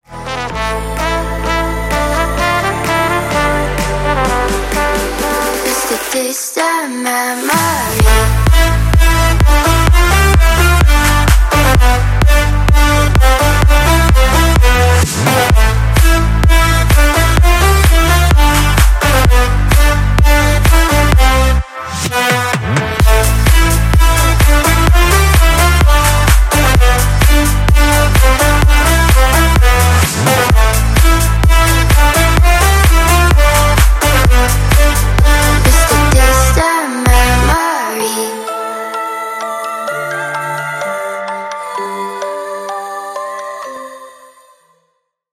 • Качество: 320, Stereo
мелодичные
Electronic
EDM
club
красивый женский голос
house